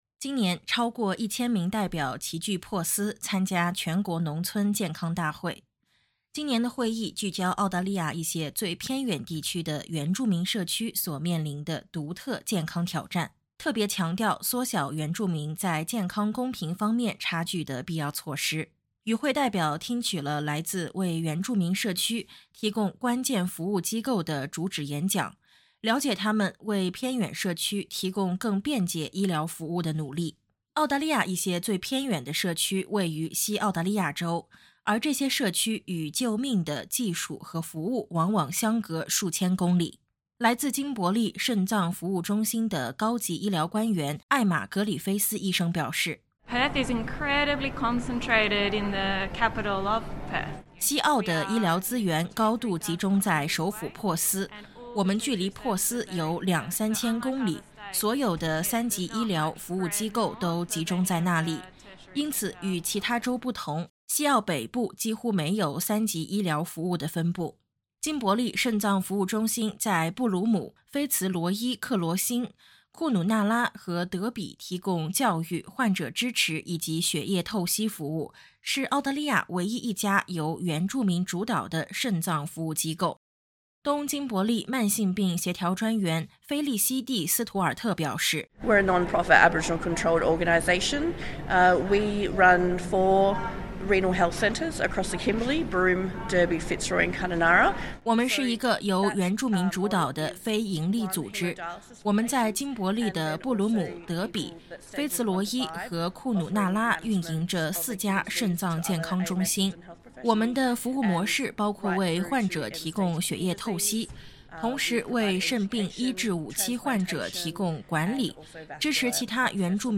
超过一千名代表齐聚珀斯，参加南半球规模最大的农村健康会议。为期三天的会议重点探讨了澳大利亚一些最偏远地区原住民社区面临的独特健康挑战。点击音频，收听综合报道。